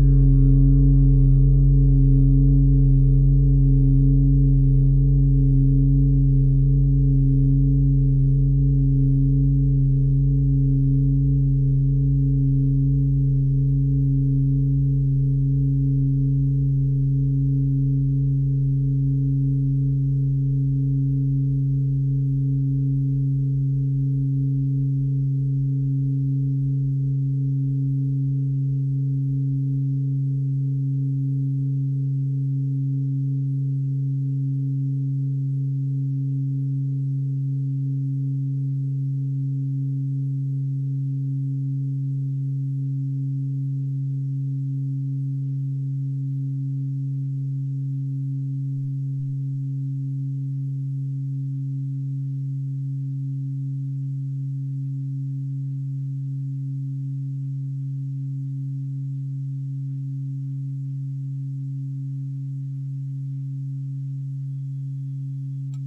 Fuss-Klangschale Nr.7, Planetentonschale: Jupiter
Klangschalen-Gewicht: 11000g
Klangschalen-Durchmesser: 53,3cm
(Ermittelt mit dem Gummischlegel)
Der Klang einer Klangschale besteht aus mehreren Teiltönen.
Die Klangschale hat bei 45.16 Hz einen Teilton mit einer
Die Klangschale hat bei 46.14 Hz einen Teilton mit einer
Die Klangschale hat bei 138.91 Hz einen Teilton mit einer
Die Klangschale hat bei 273.19 Hz einen Teilton mit einer
fuss-klangschale-7.wav